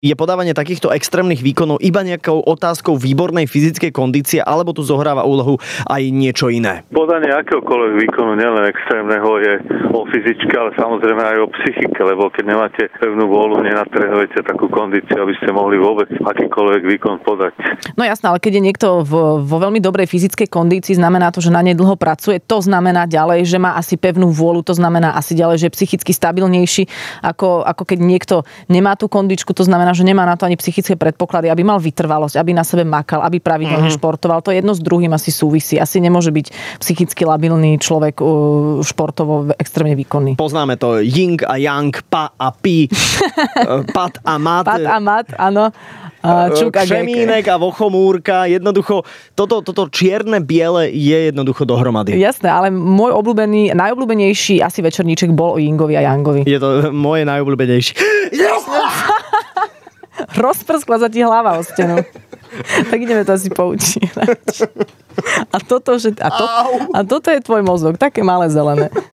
Vypočujte si to najlepšie z Rannej šou a viac o Dobrodružstvách nájdete TU